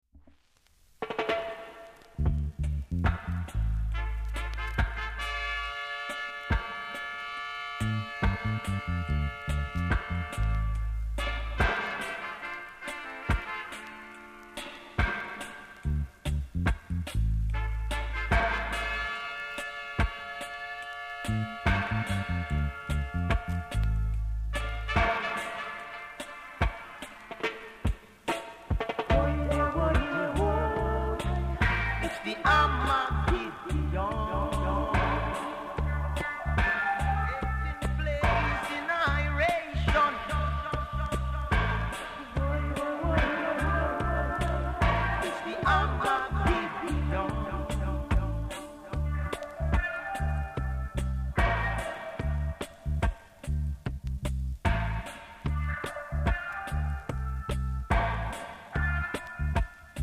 KILLER INST!!